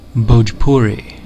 Ääntäminen
Ääntäminen US Haettu sana löytyi näillä lähdekielillä: englanti Käännöksiä ei löytynyt valitulle kohdekielelle. Määritelmät Erisnimet An Indo-Aryan language , spoken primarily in India , Mauritius , Nepal , Fiji , Surinam , Trinidad and Tobago , and Guyana .